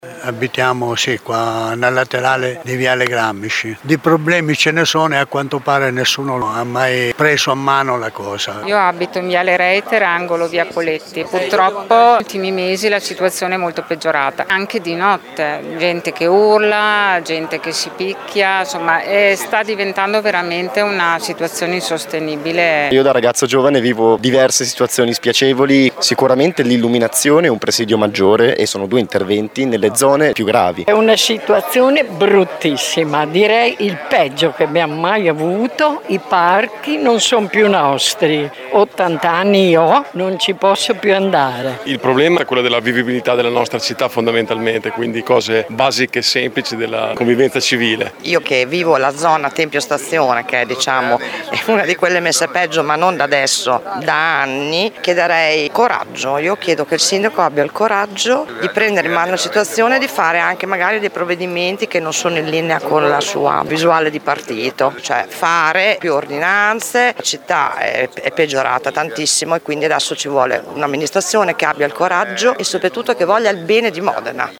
Assemblea partecipata e sala Pucci di via Canaletto gremita ieri sera dove diversi comitati di cittadini hanno espresso tutte le loro preoccupazioni in merito al peggioramento della vivibilità della città e la mancanza di sicurezza.
Qui sotto le interviste ad alcuni cittadini presenti…